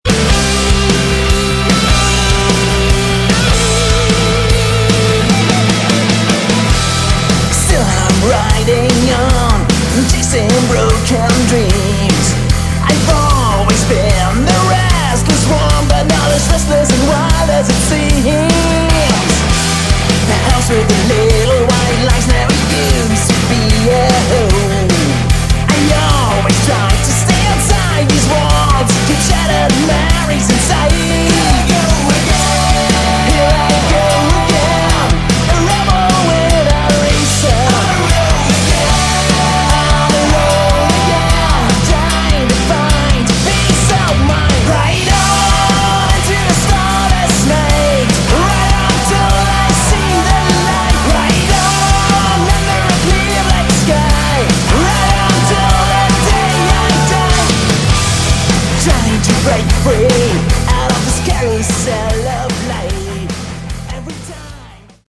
Category: Hard Rock
vocals, guitar, bass and keyboards
drums